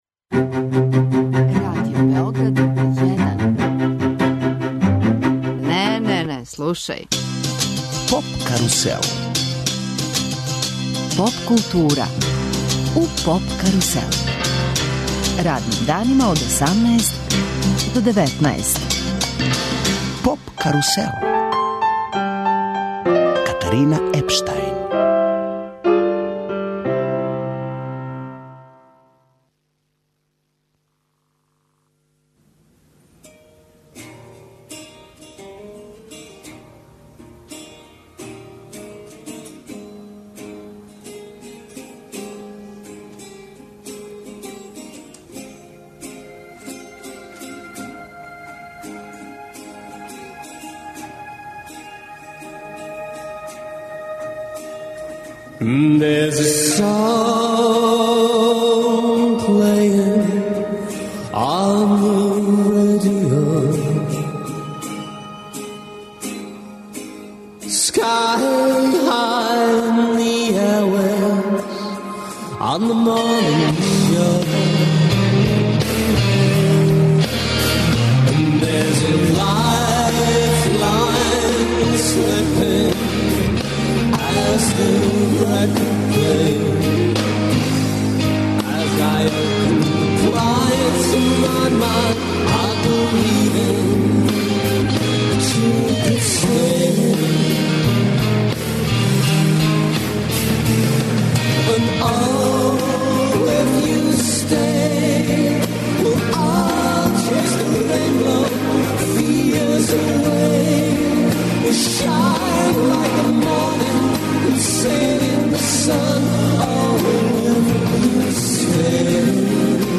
Сваког дана резимирамо претходну ноћ, слушамо извођаче и преносимо део атмосфере.